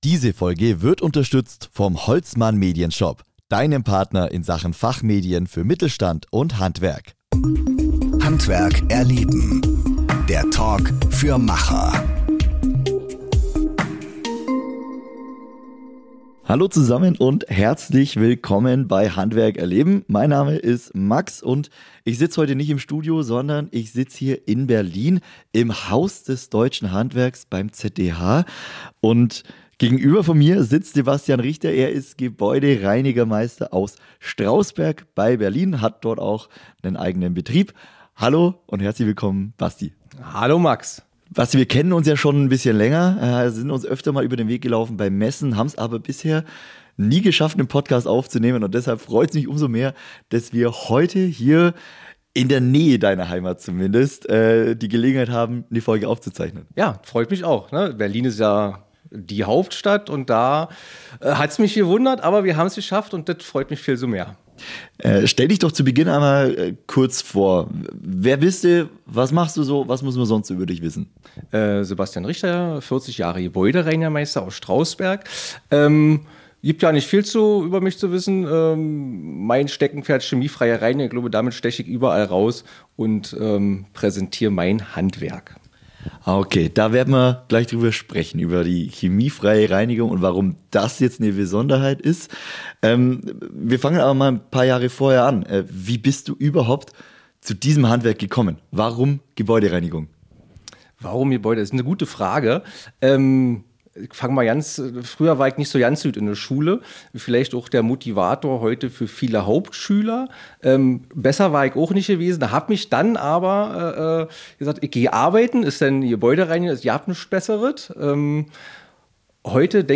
Ein ehrliches, inspirierendes Gespräch über Leidenschaft, Qualität und die Wertschätzung eines oft unterschätzten Gewerks.